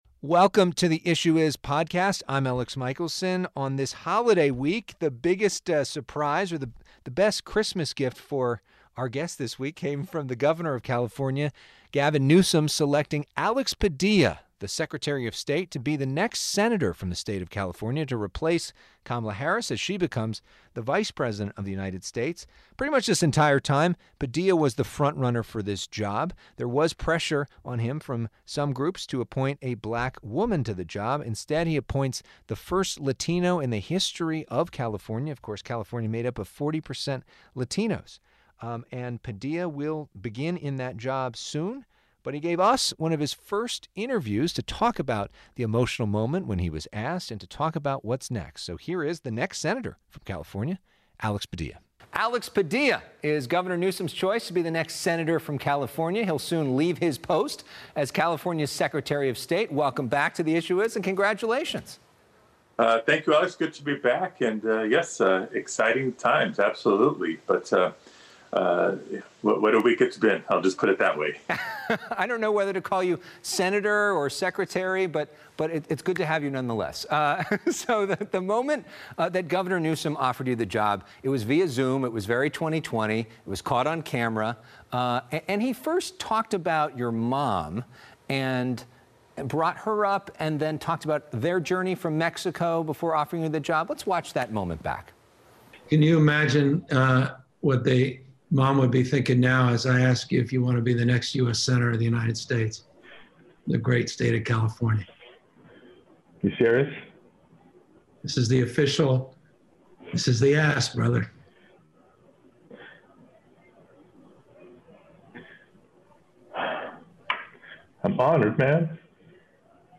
One-on-one with California Secretary of State Alex Padilla